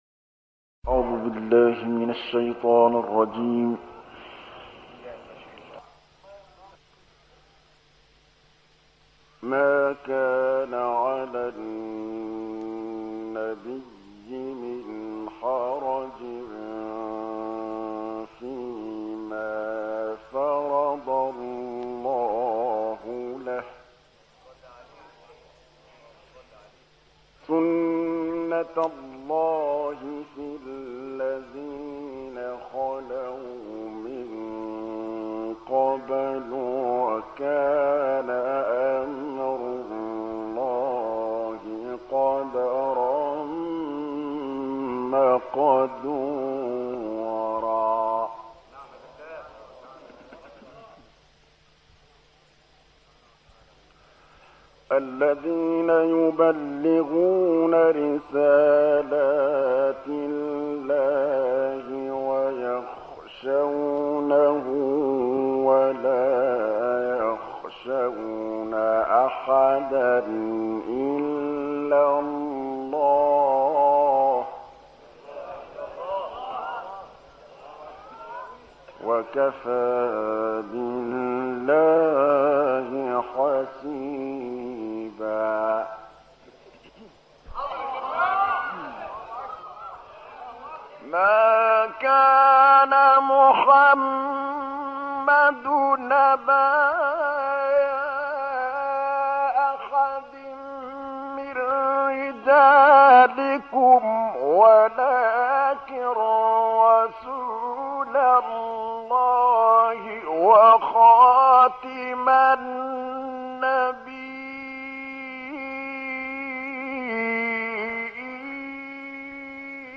صوت | تلاوت «طبلاوی» از آیات سوره احزاب
به مناسبت هفته وحدت و سالروز میلاد پیامبر مهربانی، تلاوت آیات 38 تا 44 سوره مبارکه احزاب را با صوت محمد محمود طبلاوی، قاری مشهور مصری می‌شنوید. در این تلاوت که در مسجد الحامدی شهر قاهره اجرا شده است، آیه خاتمیت تنها آیه‌ای که به صراحت حضرت محمد(ص) را آخرین پیامبر معرفی می‌کند، قرائت می‌شود.